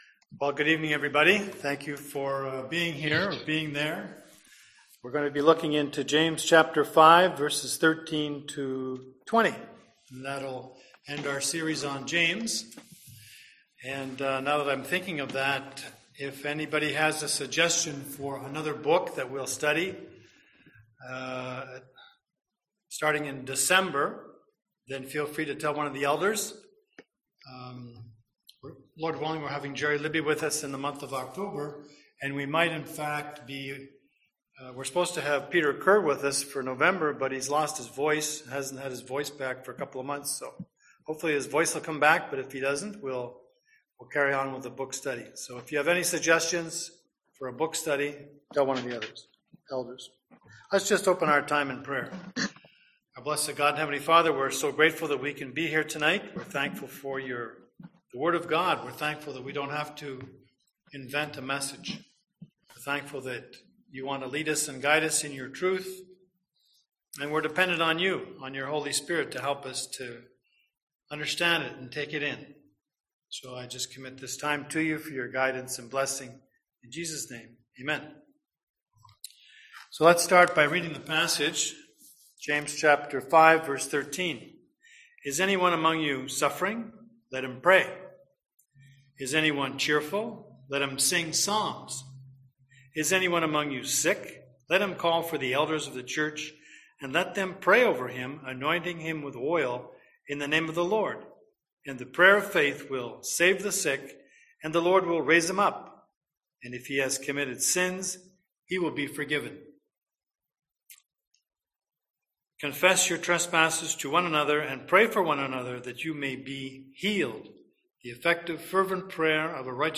Passage: James 5:13-20 Service Type: Seminar